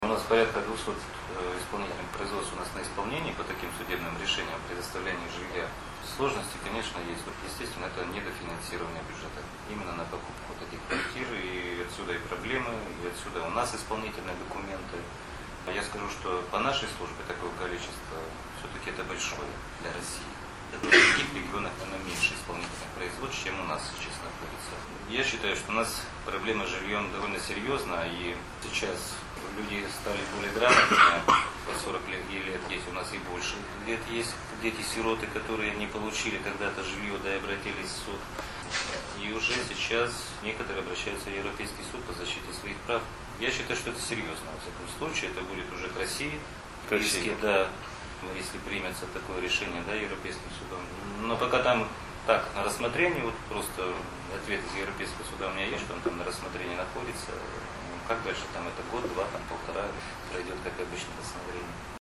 Об этом рассказал заместитель руководителя УФССП России по Вологодской области Борис Кочин на пресс-конференции в ИА «СеверИнформ», посвященной защите прав детей.